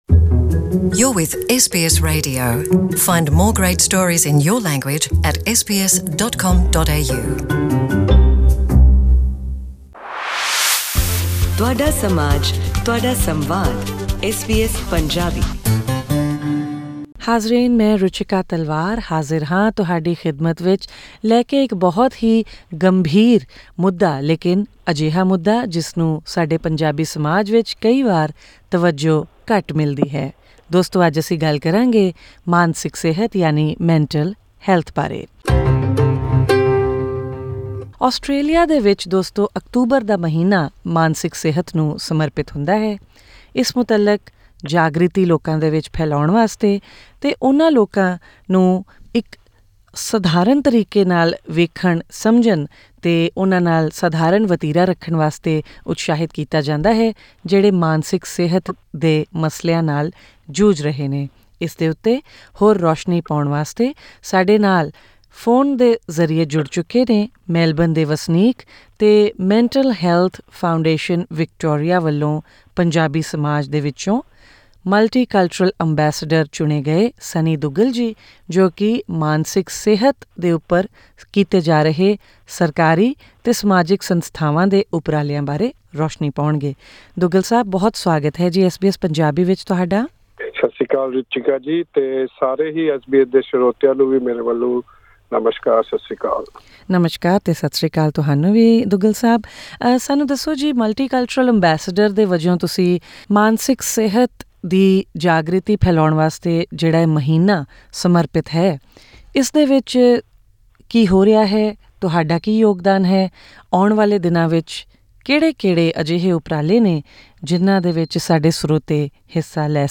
To listen to this interview in Punjabi, click on the player at the top of the page.